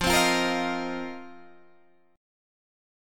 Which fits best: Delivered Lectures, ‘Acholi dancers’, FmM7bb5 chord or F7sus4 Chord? F7sus4 Chord